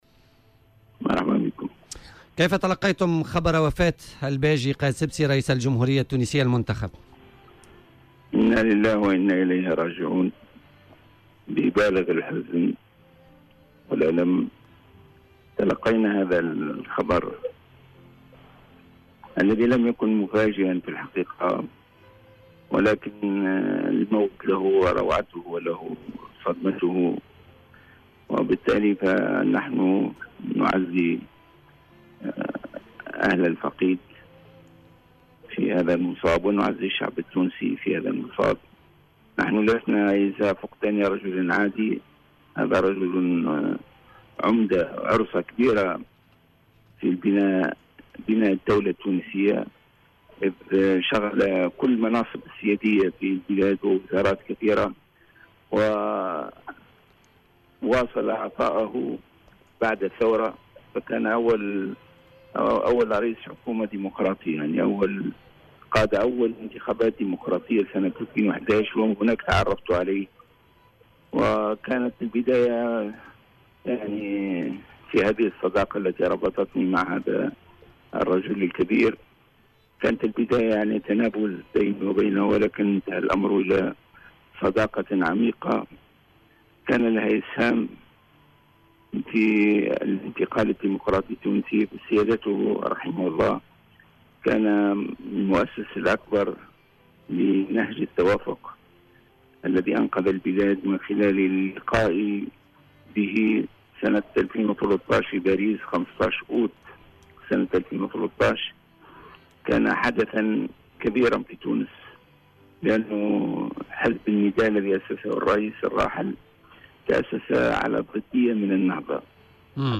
أدلى رئيس حركة النهضة راشد الغنوشي بشهادة مؤثّرة لـ "الجوهرة أف أم" حول الرئيس الراحل الباجي قايد السبسي الذي وافته المنية صباح اليوم الخميس المصادف لعيد الجمهورية في تونس.